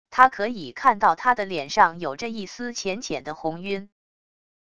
他可以看到他的脸上有着一丝浅浅的红晕wav音频生成系统WAV Audio Player